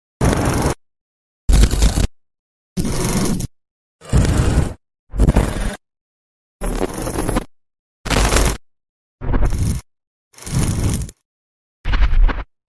Light leak filmburn trasisi yang sound effects free download